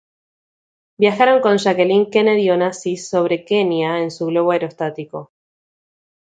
a‧e‧ros‧tá‧ti‧co
/aeɾosˈtatiko/